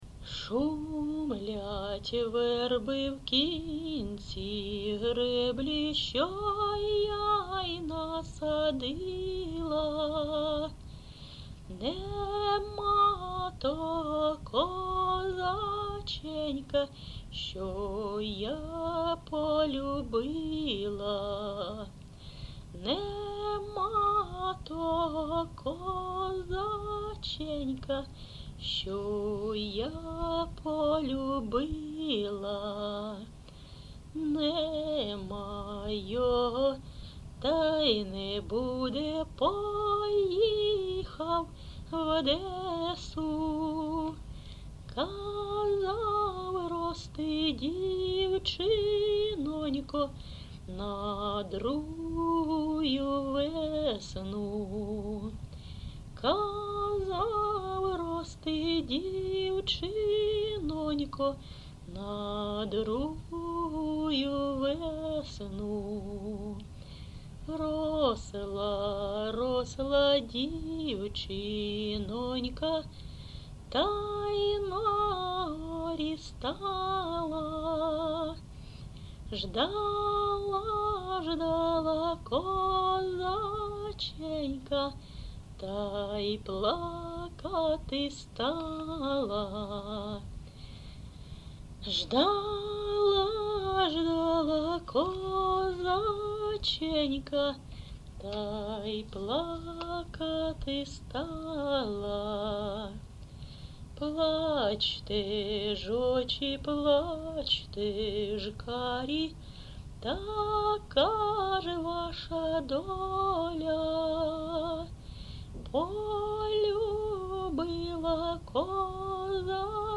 ЖанрПісні з особистого та родинного життя
Місце записум. Ровеньки, Ровеньківський район, Луганська обл., Україна, Слобожанщина